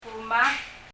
（発音の背後に雑音がはいっています。 教室の外で、おじさんが車の解体だか、修理だか、やっていて、ときどきハンマーで金属板をひっぱたく音が入っています、悪しからずご容赦を）
kuma [kumə] （発音の背後に雑音がはいっています。